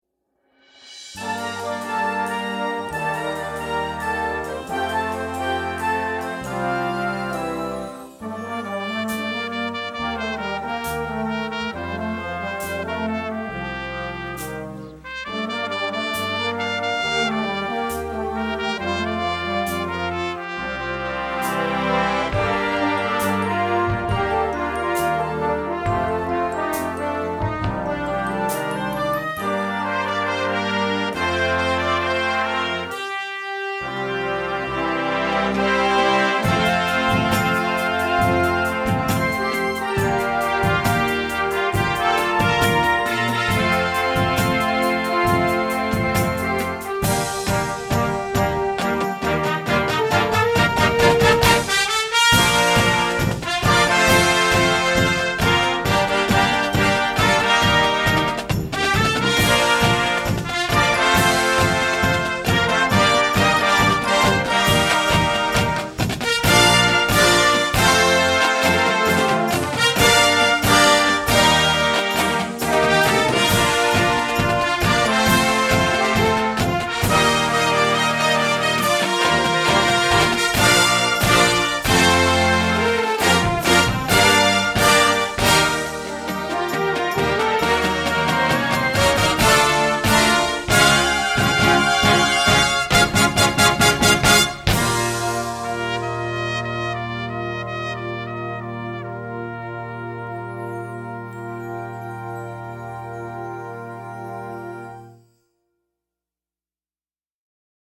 Marching Band
Besetzung: Blasorchester